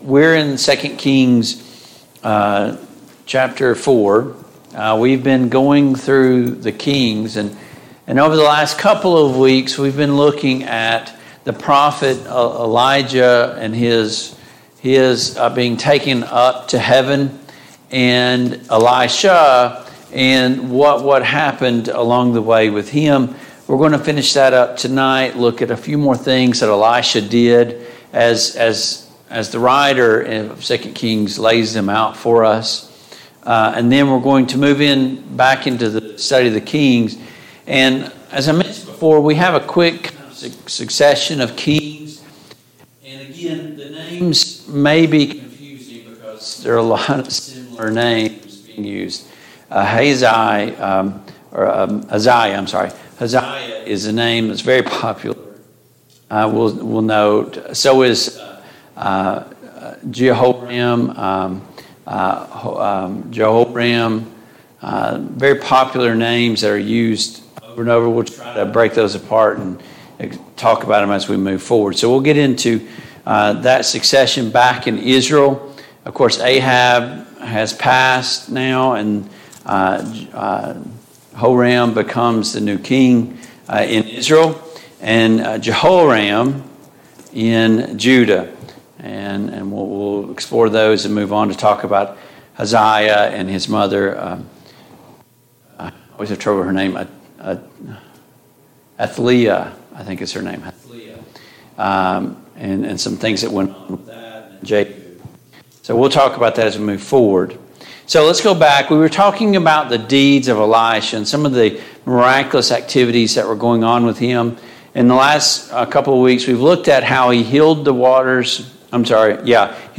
The Kings of Israel Passage: 2 Kings 5, 2 Kings 6, 2 Kings 7, 2 Kings 8 Service Type: Mid-Week Bible Study